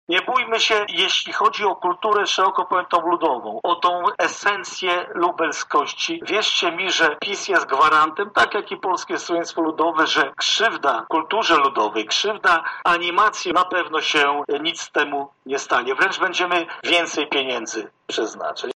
Wojewódzki Ośrodek Kultury będzie nadal pełnił te same funkcje – zapewniał marszałek województwa lubelskiego Jarosław Stawiarski: